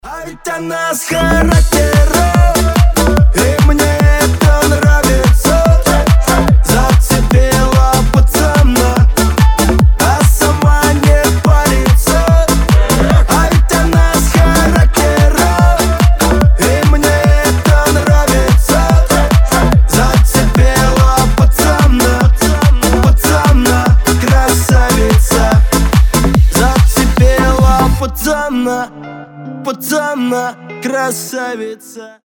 • Качество: 320, Stereo
позитивные
веселые